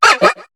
Cri de Couaneton dans Pokémon HOME.